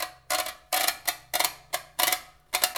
Index of /90_sSampleCDs/Roland L-CD701/PRC_FX Perc 1/PRC_Long Perc
PRC GRATER07.wav